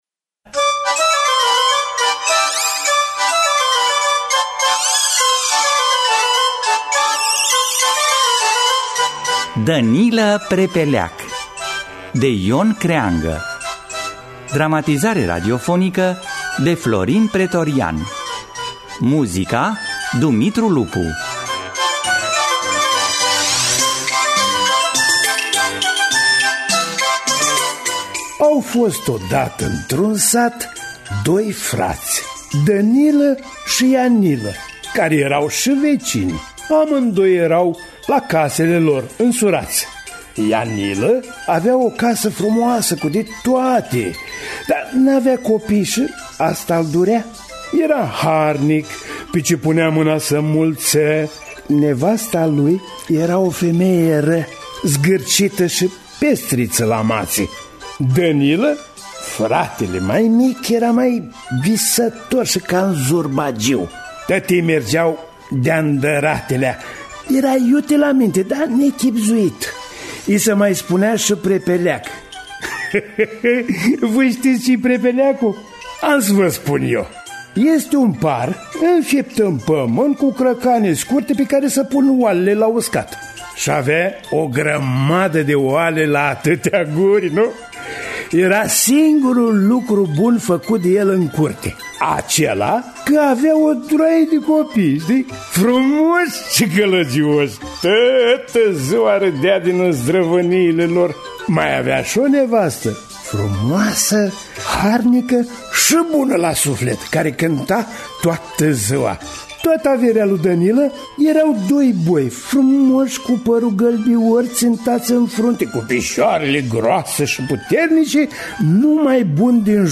Dramatizare radiofonică